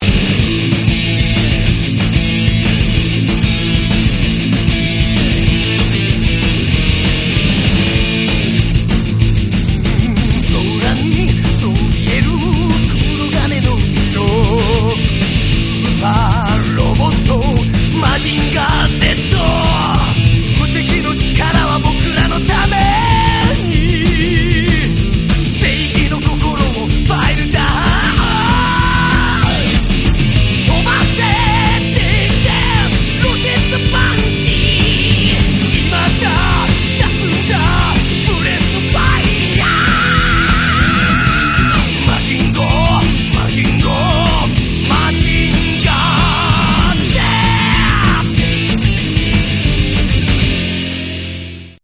Version metalica del tema principal